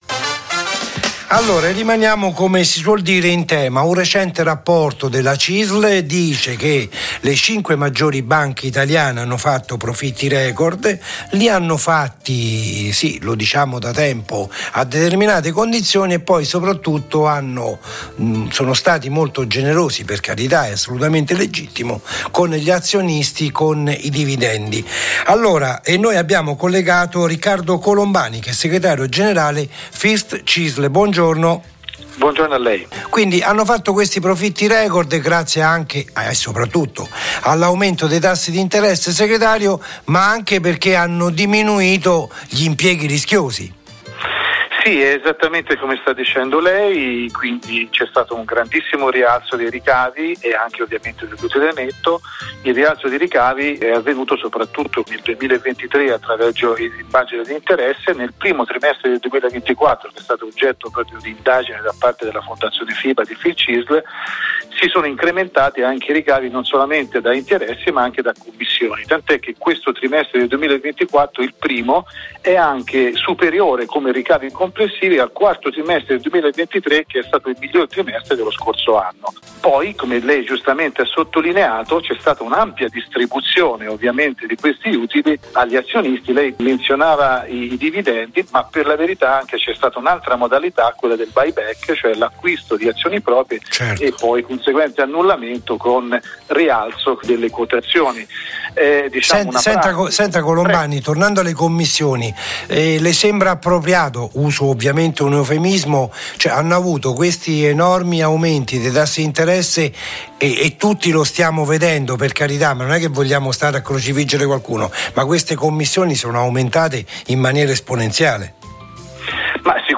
la trasmissione di Rai Radio 1